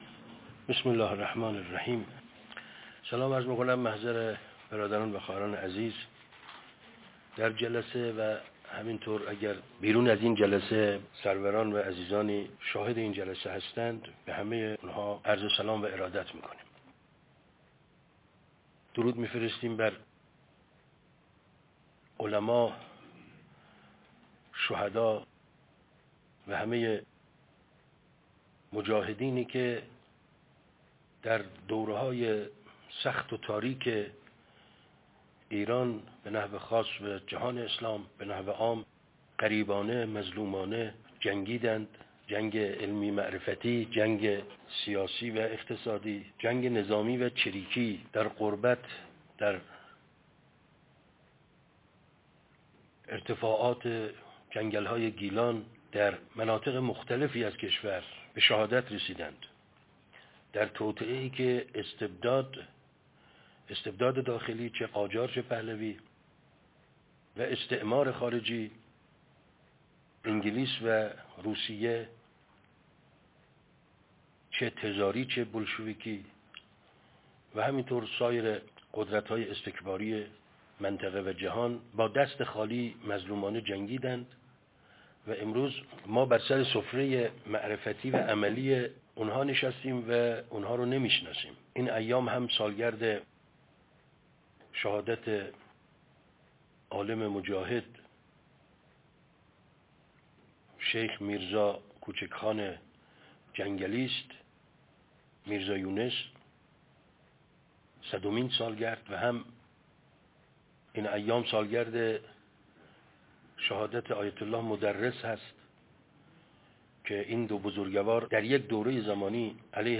شبکه یک - 19 آذر 1400